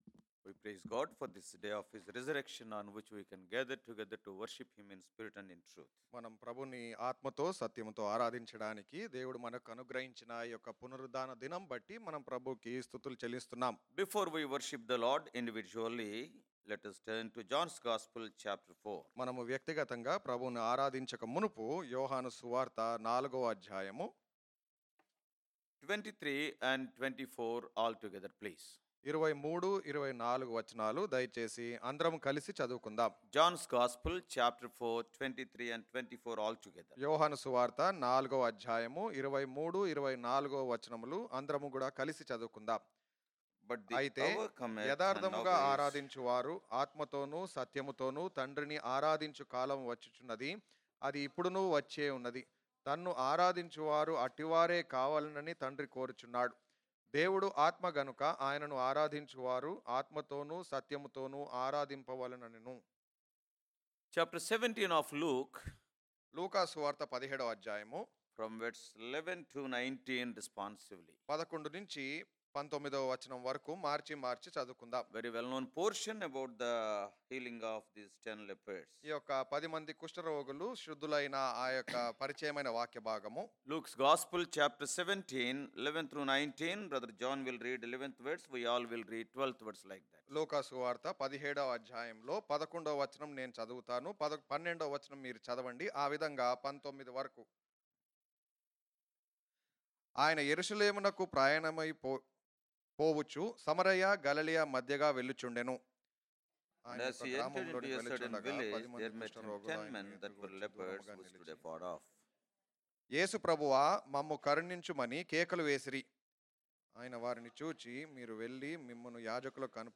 Worship Message
WorshipMessage.mp3